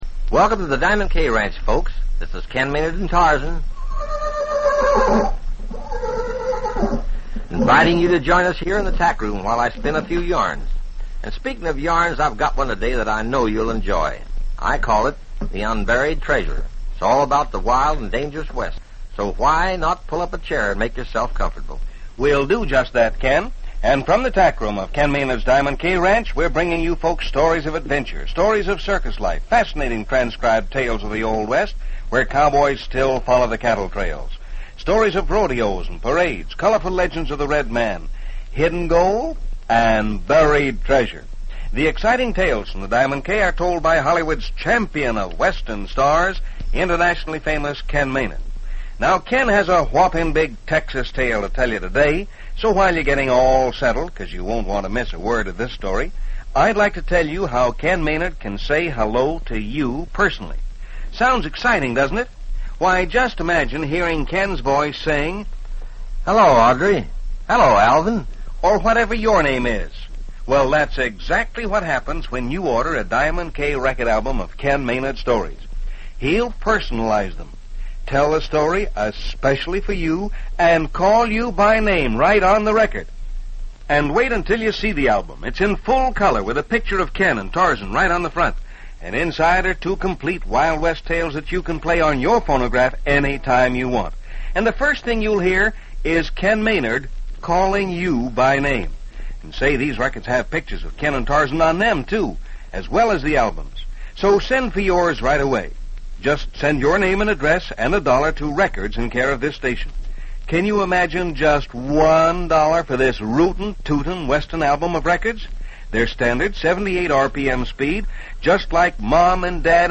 "Tales from the Diamond K" was a syndicated radio show aimed at a juvenile audience, broadcasted during the mid-1950s. - The show featured a variety of stories, mostly set in the Old West, and was designed to entertain and educate its young listeners. - Ken Maynard, a pioneer singing cowboy and film star, hosted the show, introducing a different story each day.